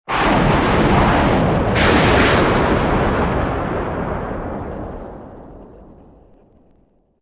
SolarFlare.wav